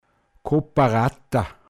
pinzgauer mundart
Kooperator, Kaplan Koparata, m.